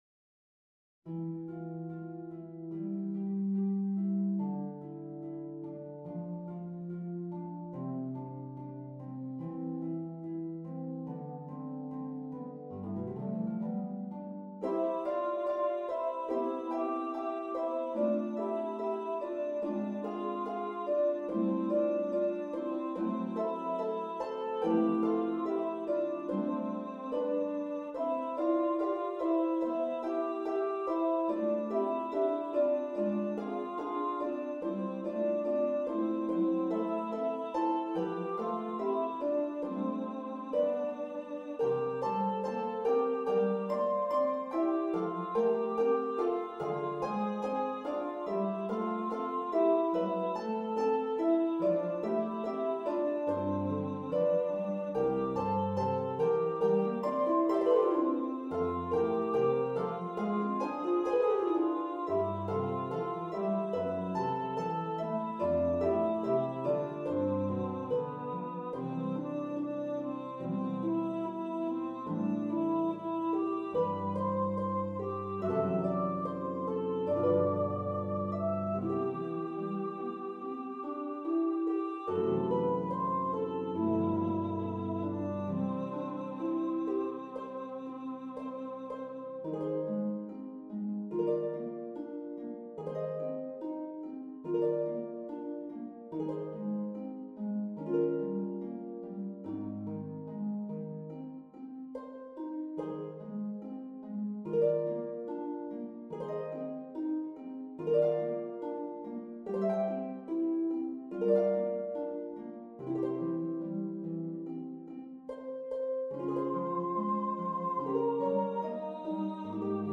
for voice and harp
Voice solo (with accompaniment)